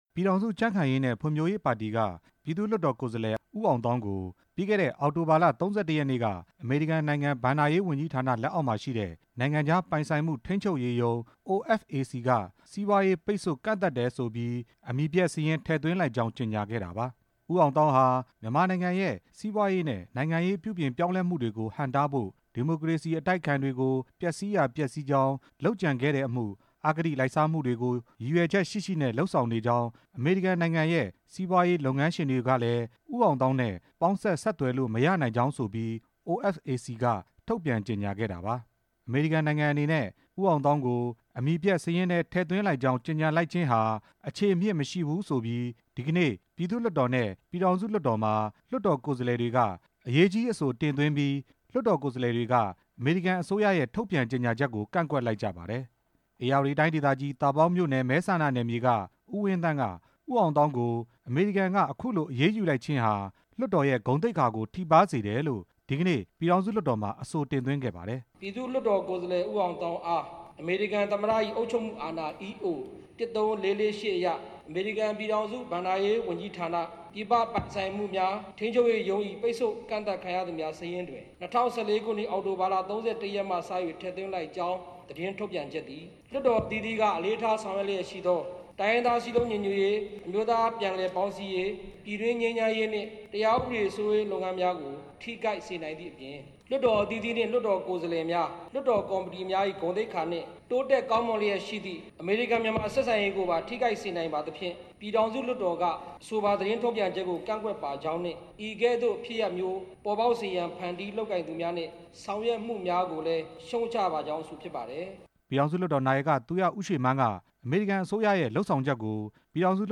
ဦးအောင်သောင်းအပေါ် ပိတ်ဆို့အရေးယူတာဟာ လွှတ်တော်ကိုယ်စားလှယ်တွေရဲ့ ဂုဏ်သိက္ခာနဲ့ အမေရိကန်နဲ့ မြန်မာ နှစ်နိုင်ငံဆက်ဆံရေးမှာ ထိခိုက်မှုတွေမဖြစ်အောင် အမေရိကန်အစိုးရရဲ့ အရေးယူမှုကို ပြည်ထောင်စုလွှတ်တော်က ကန့်ကွက်ကြောင်း ပြည်ထောင်စုလွှတ်တော်နာယက သူရဦးရွှေမန်းက အခုလိုဖတ်ပြခဲ့ပါတယ်။